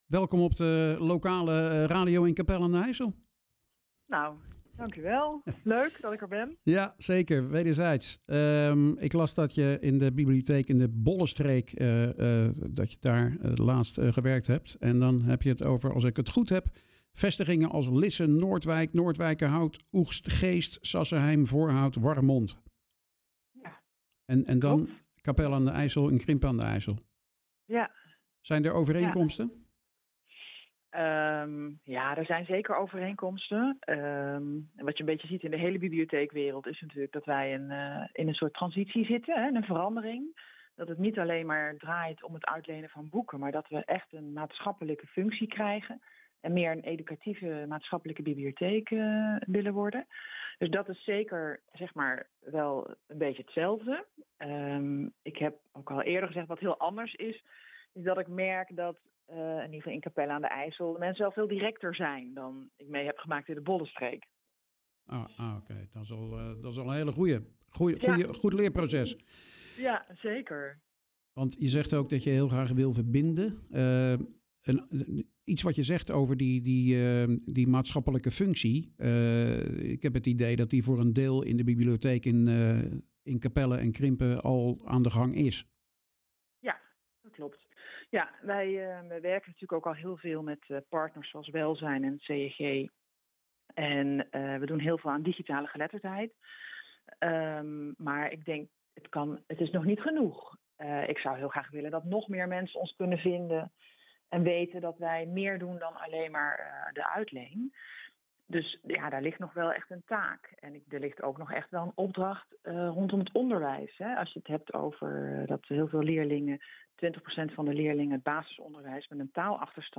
Het is tijd voor een nadere kennismaking via een gesprek dat gaat over de visie en ambitie voor de komende jaren.